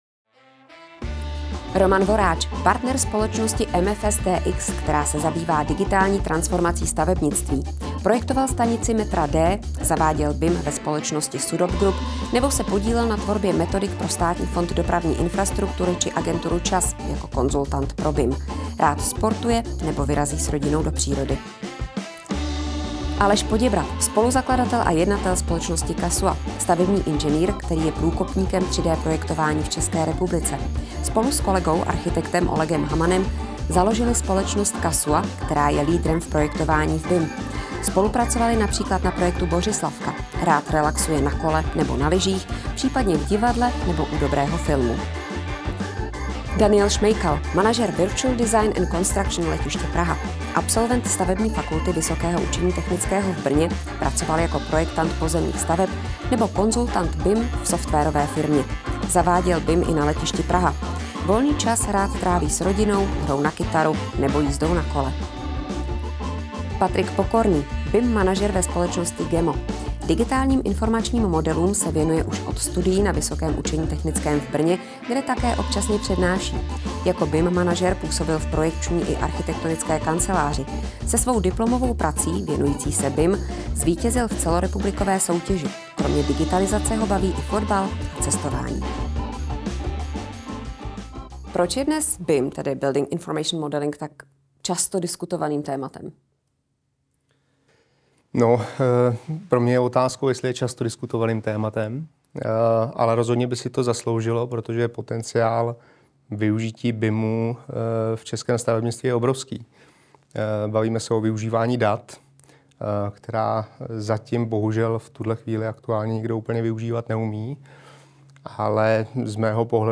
diskuze